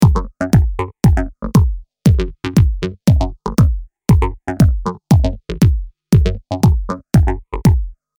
начинается сначала (кислотный, acid) как тут =audio&c[q